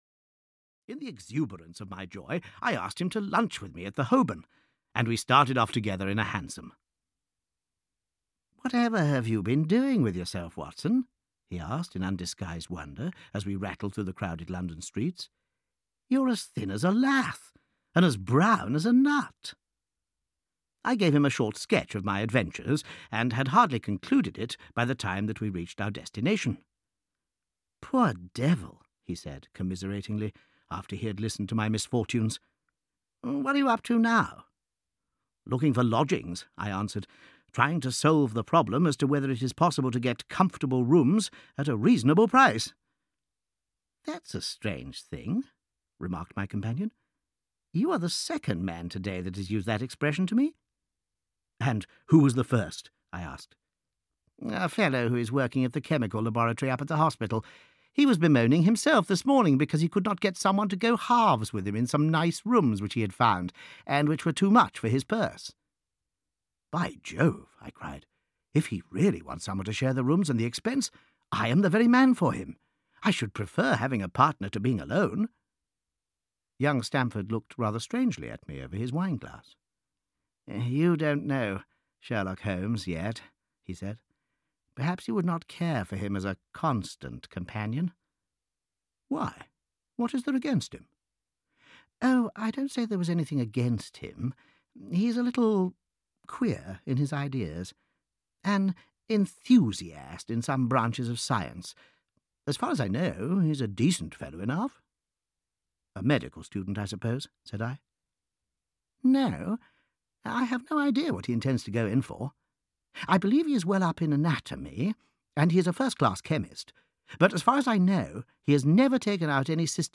A Study in Scarlet (EN) audiokniha
Ukázka z knihy